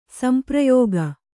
♪ samprayōga